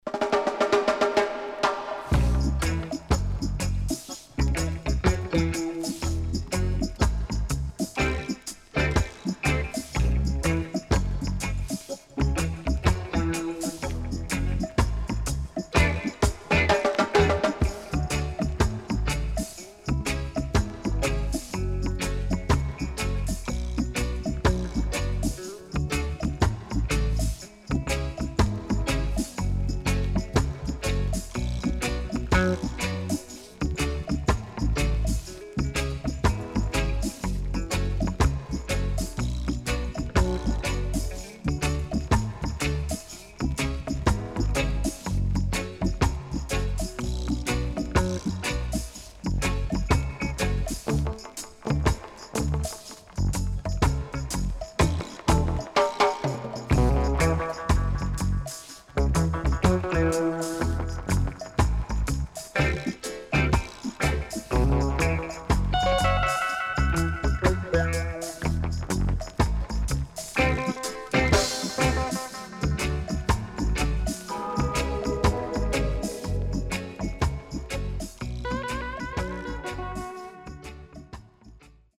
HOME > REGGAE / ROOTS  >  定番70’s
SIDE A:少しチリノイズ入りますが良好です。